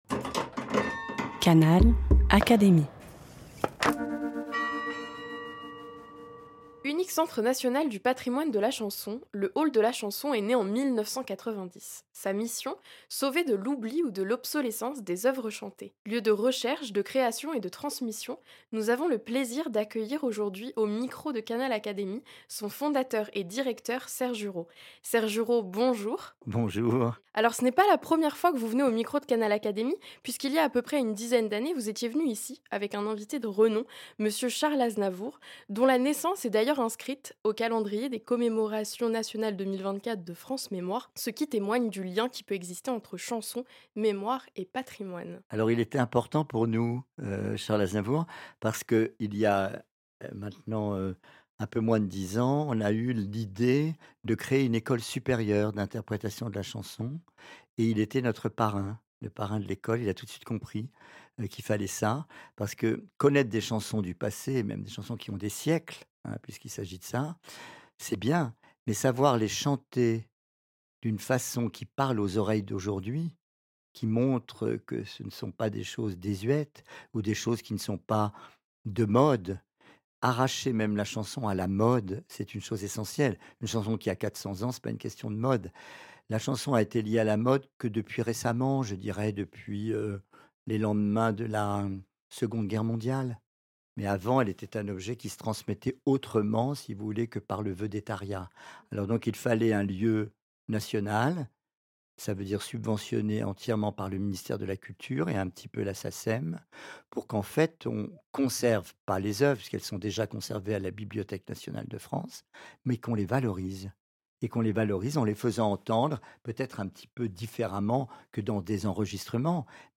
chant
accordéon